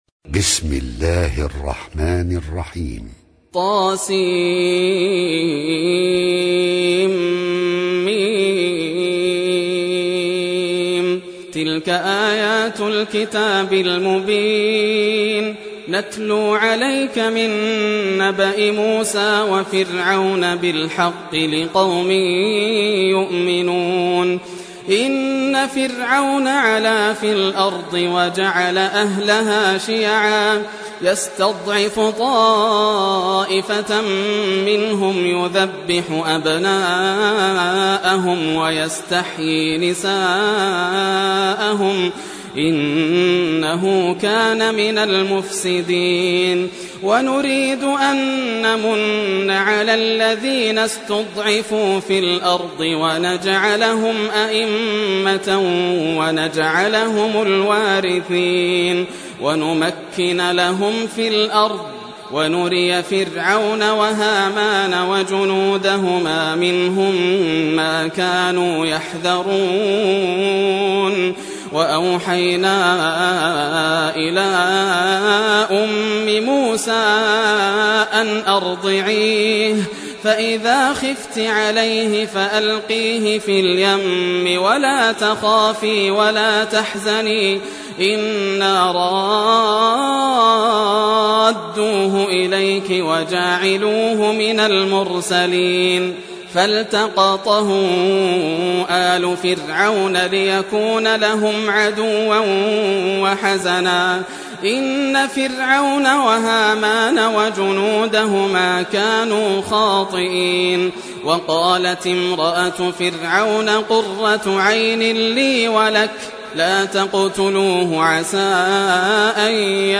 Surah Al-Qasas Recitation by Yasser Al Dosari
Surah Al-Qasas, listen or play online mp3 tilawat / recitation in Arabic in the beautiful voice of Sheikh Yasser al Dosari.